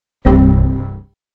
Стандартная ошибка Windows